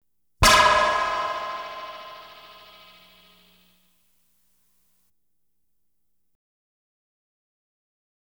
Light Beam Hit Sound Effect
Download a high-quality light beam hit sound effect.
light-beam-hit-1.wav